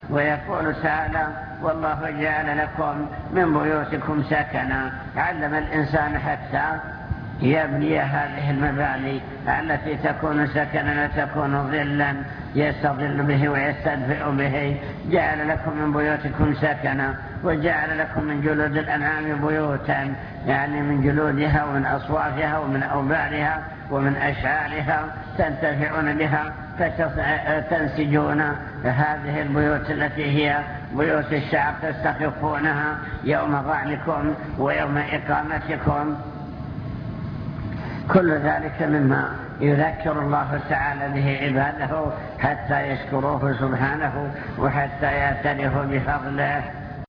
المكتبة الصوتية  تسجيلات - محاضرات ودروس  محاضرة بعنوان شكر النعم (2) امتنان الله تعالى على عباده بما أنعم عليهم